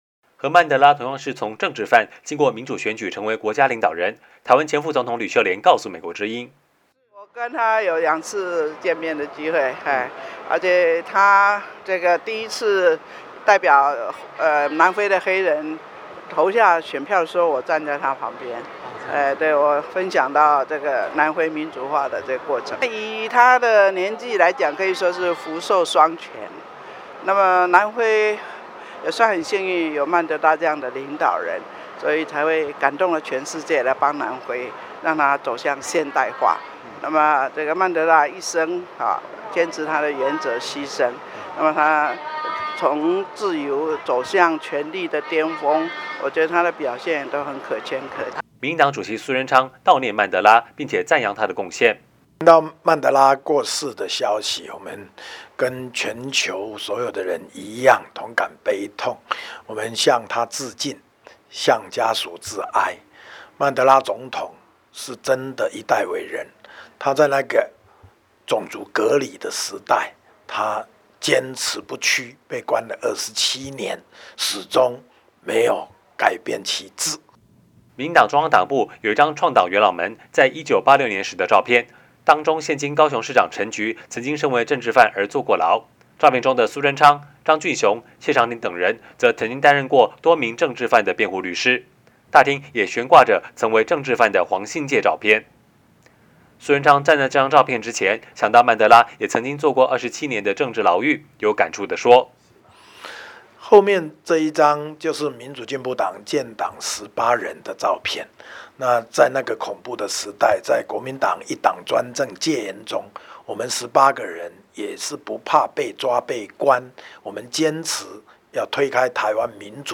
台北 —  曾为政治犯的南非前总统曼德拉过世，为追求民主也受过牢狱之灾的台湾前副总统吕秀莲感同身受，曾与曼德拉两次会面的她，赞扬曼德拉的成就。民进党主席苏贞昌在接受美国之音专访时，除了悼念曼德拉，也从曼德拉生平回顾民进党人为民主不惜坐牢的精神。